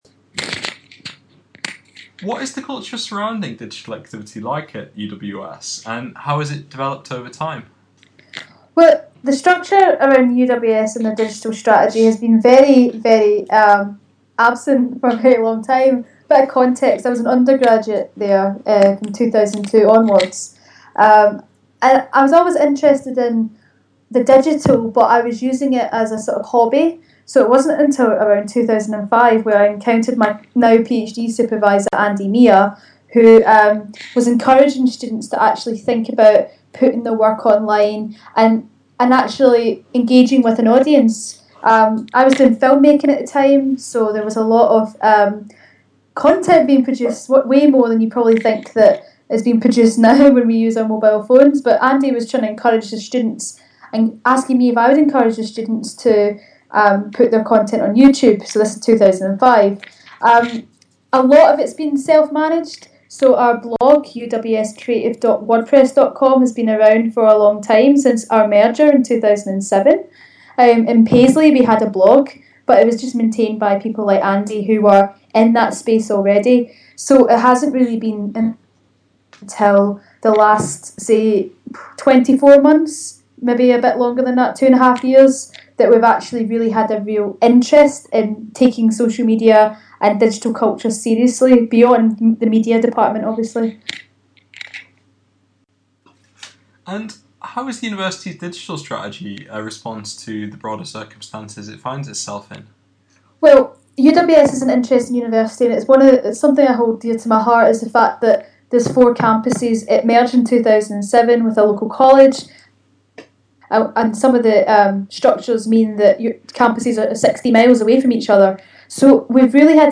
A podcast interview